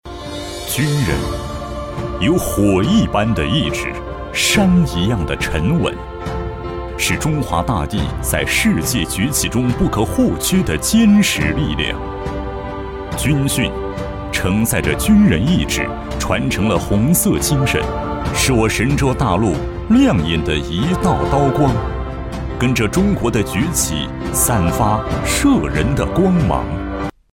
成熟稳重 企业专题,人物专题,医疗专题,学校专题,产品解说,警示教育,规划总结配音
大气沉稳，磁性男音，擅自记录片、专题汇报、宣传片、讲述等题材。